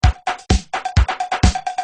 Free MP3 LinnDrum - LM1 & LM2 - Loops 4